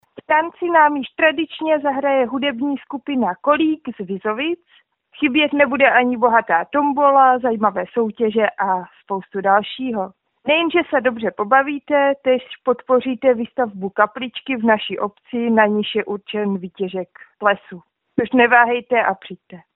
Pozvánka